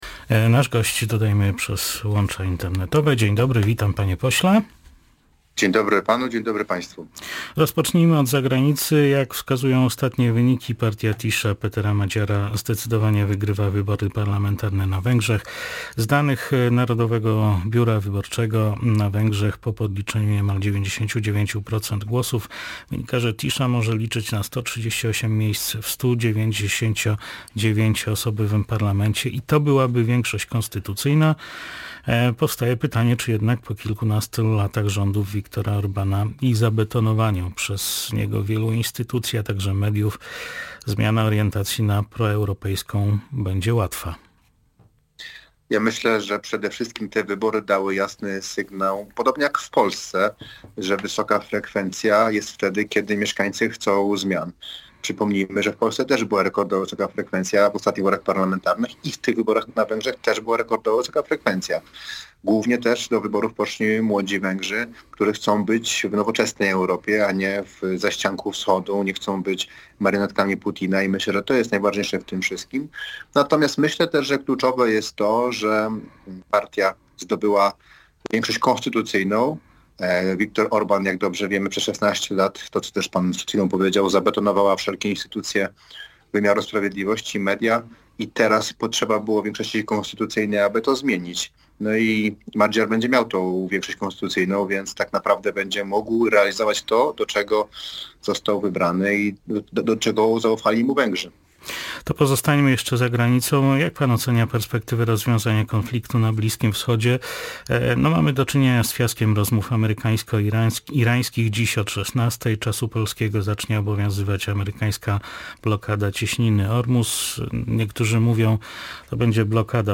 Gość dnia • Zmiana na węgierskiej scenie politycznej dokonała się dzięki wysokiej frekwencji wyborczej – mówił na naszej antenie poseł Bartosz Romowicz z Polski 2050.